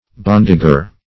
Search Result for " bondager" : The Collaborative International Dictionary of English v.0.48: Bondager \Bond"a*ger\ (-[asl]*j[~e]r), n. A field worker, esp. a woman who works in the field.